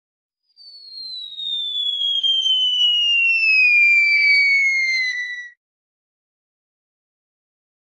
Falling Téléchargement d'Effet Sonore
Falling Bouton sonore